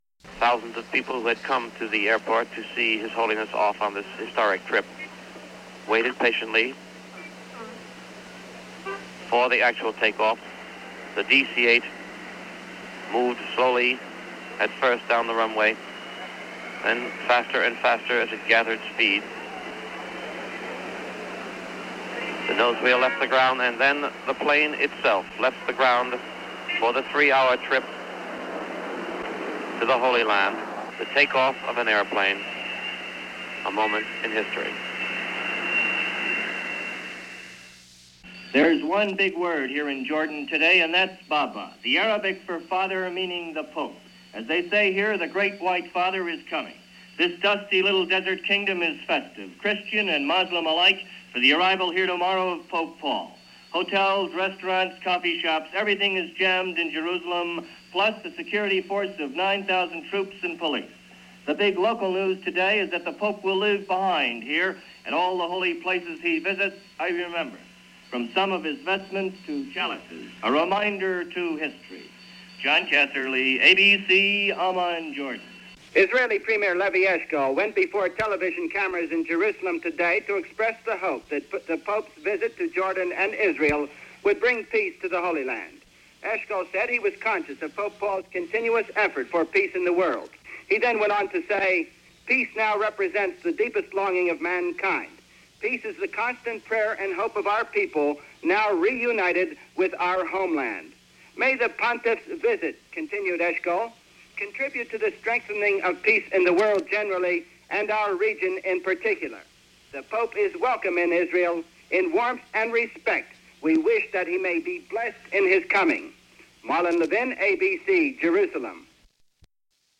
reports from Middle East Trip – ABC Radio